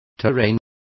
Complete with pronunciation of the translation of terrain.